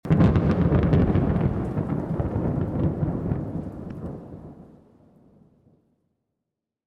thunder_2.mp3